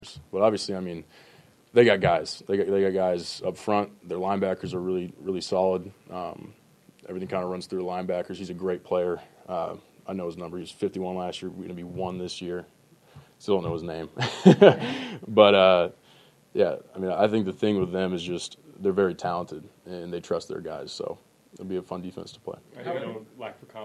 Florida quarterback Graham Mertz previewed a new season of Gator football and the opening matchup against No. 19 Miami in a news conference Monday.